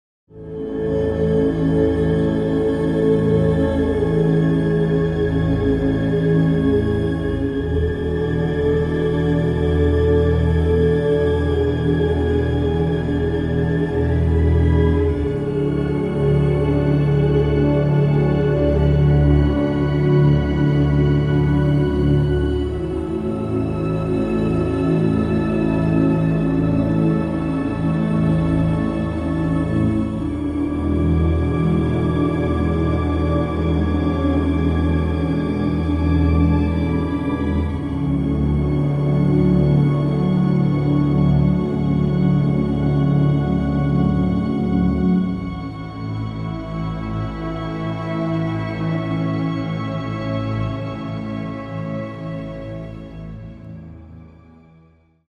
The sound of the tomahawk sound effects free download
The sound of the tomahawk hitting